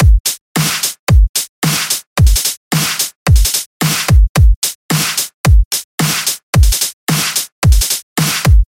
Groovy Drums 165bpm
描述：Groovy drums that fit with the Groovy Grit loops.
标签： 165 bpm Dubstep Loops Drum Loops 1.46 MB wav Key : Unknown
声道立体声